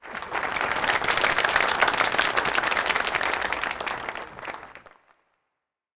Applause Sound Effect
Download a high-quality applause sound effect.
applause-6.mp3